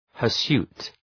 Προφορά
{‘hɜ:rsu:t}
hirsute.mp3